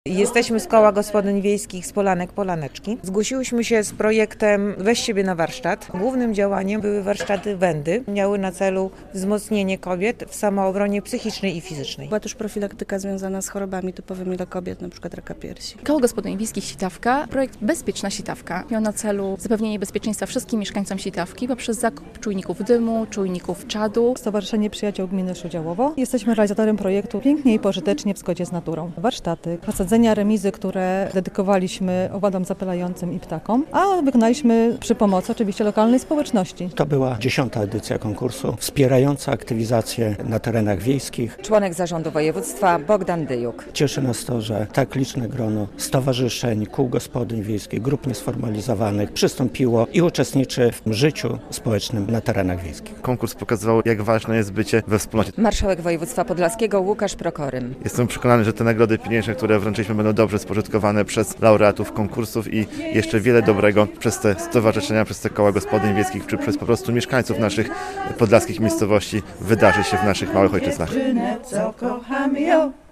Rozstrzygnięcie X edycji "Olimpiady Aktywności Wiejskiej" - relacja